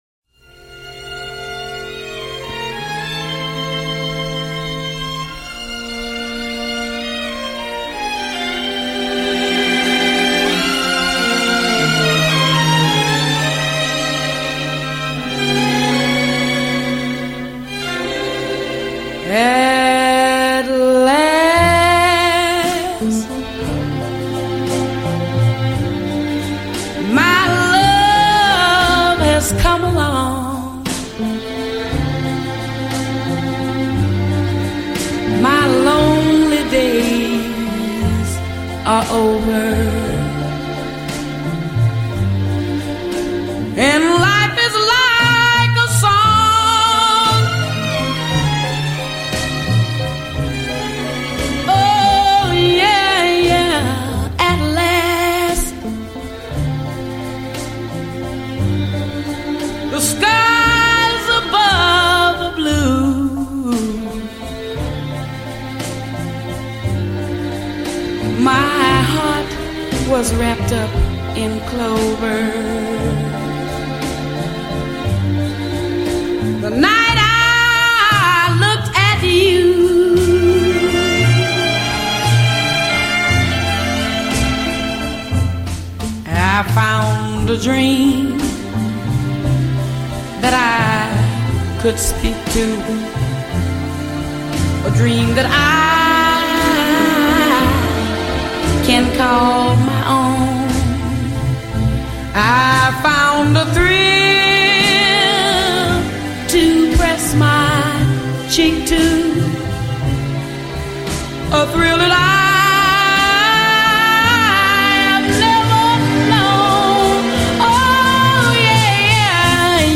.. and audio all in F